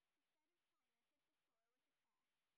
sp20_white_snr10.wav